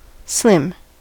En-us-slim.WAV